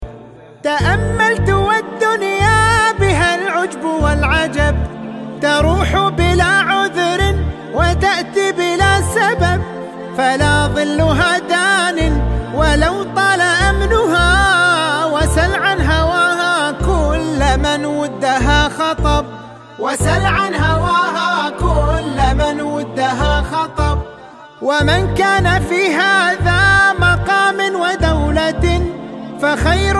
آهنگ زنگ غمگین عربی